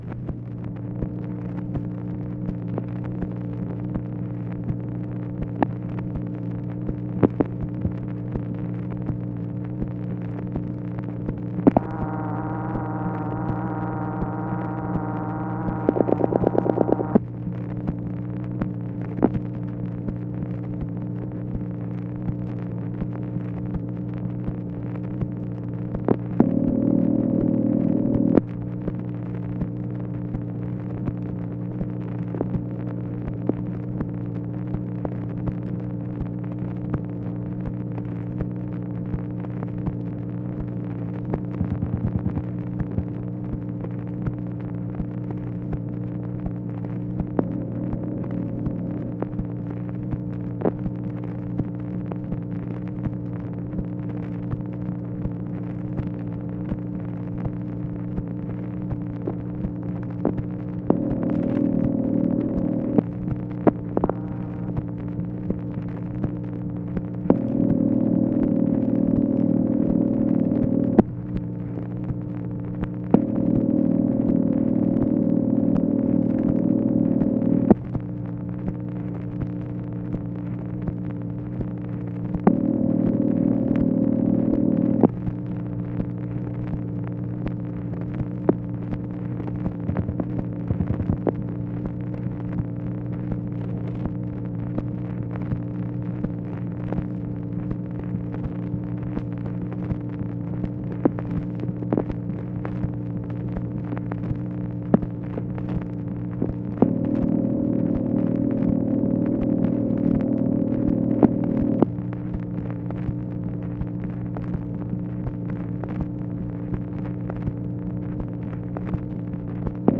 MACHINE NOISE, January 4, 1965
Secret White House Tapes | Lyndon B. Johnson Presidency